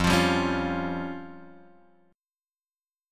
Fsus2#5 chord {1 x 3 0 2 1} chord